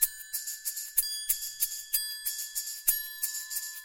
描述：三种打击乐器围绕一个麦克风演奏三角铁、手鼓和摇蛋器。3/4拍。
Tag: 3 3-4 4 打击乐器 节奏 振动筛 铃鼓 三角形 各种